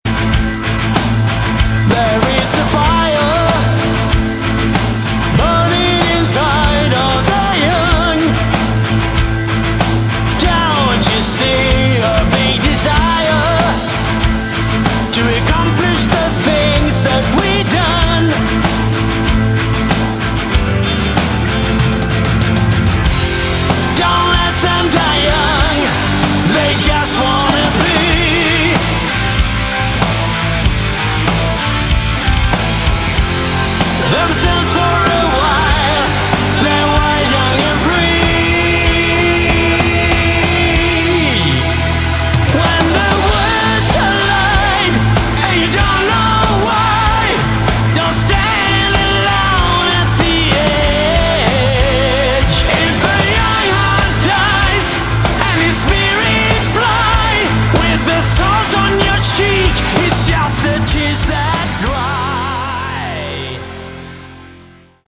Category: Hard Rock
bass
guitar
vocals
keyboards
drums